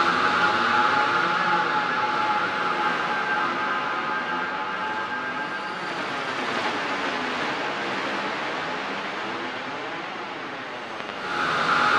Ambient
1 channel